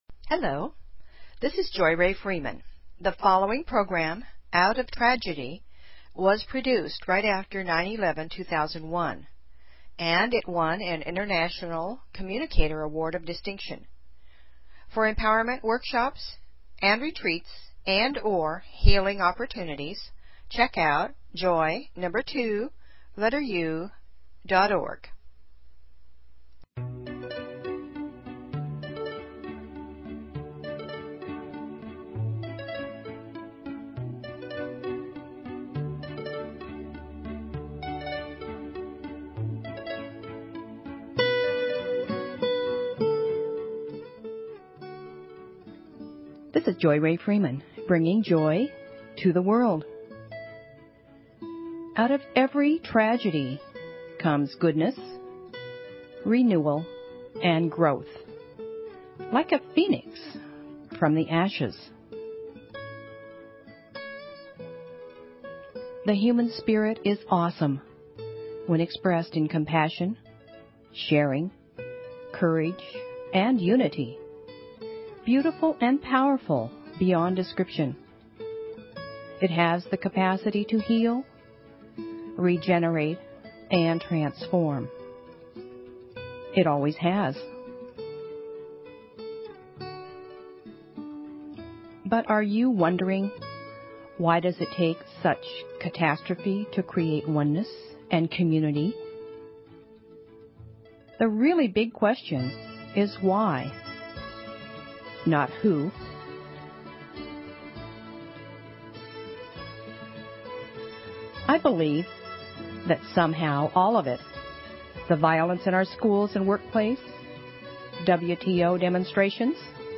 Talk Show Episode, Audio Podcast, Joy_To_The_World and Courtesy of BBS Radio on , show guests , about , categorized as
JOY TO THE WORLD - It's a potpourri of music, INSPIRATION, FOLKSY FILOSOPHY, POETRY, HUMOR, STORY TELLING and introductions to people who are making a difference. It's lively, but not rowdy - it's sometimes serious, but not stuffy - it's a little funny, but not comical - and most of all - it's a passionate, sincere sharing from my heart to yours.